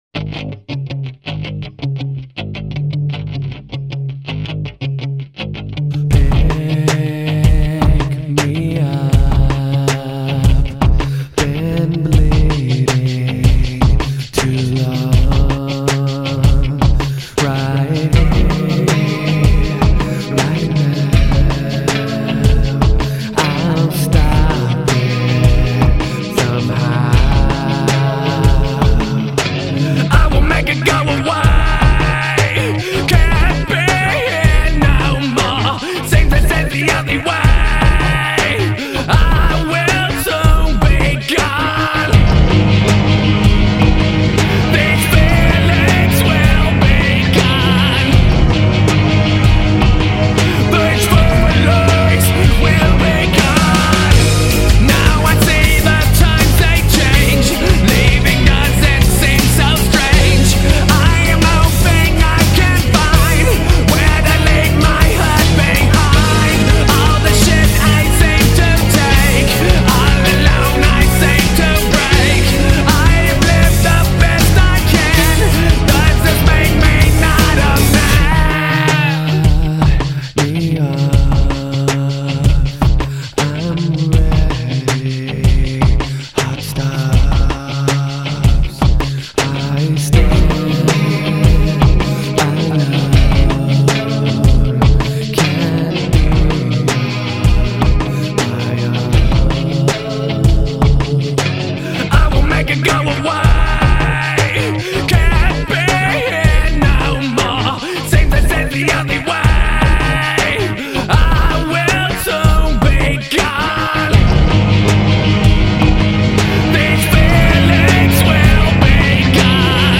La Zona con todo sobre este grupo Nu-Aggro Metal